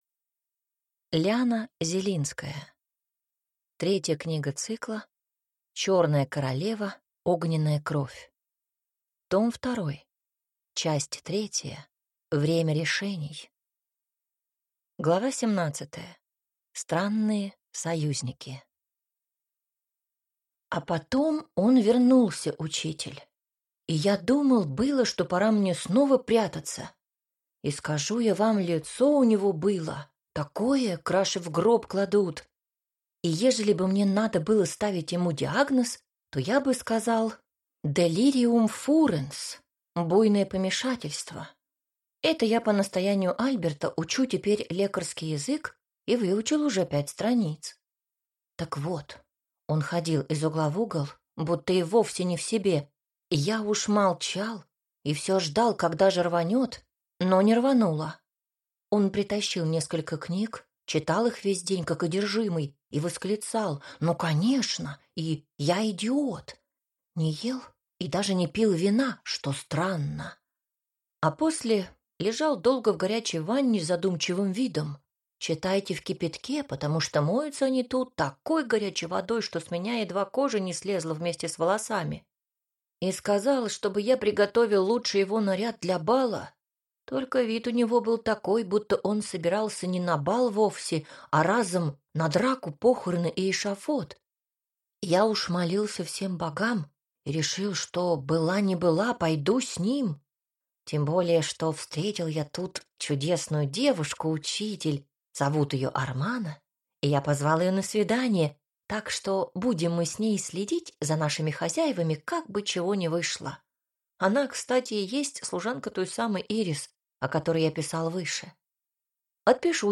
Аудиокнига Огненная кровь. Том 2 | Библиотека аудиокниг